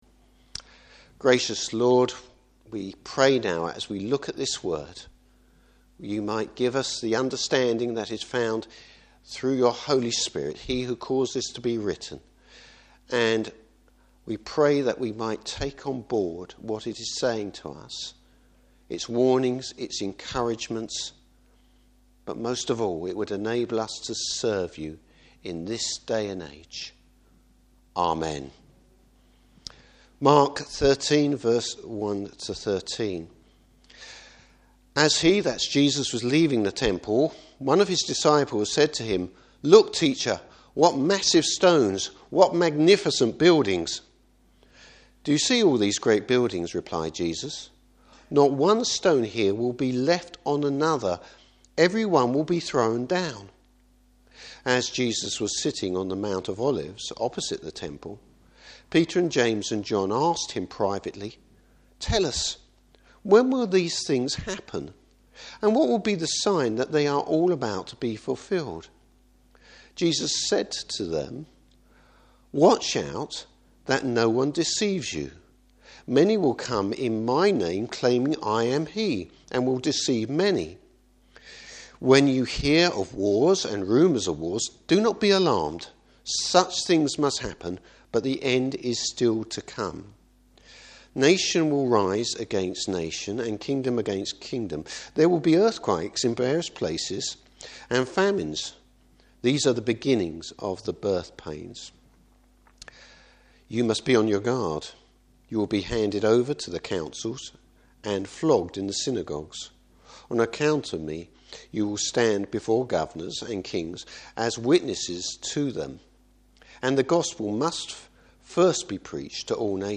Service Type: Morning Service Jesus encourages His disciple’s about the future.